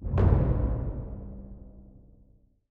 pgs/Assets/Audio/Fantasy Interface Sounds/Special Click 06.wav at master
Special Click 06.wav